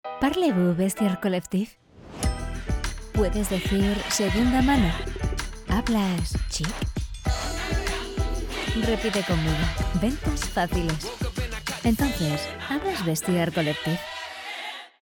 Kommerziell, Zugänglich, Vielseitig, Warm, Sanft
Kommerziell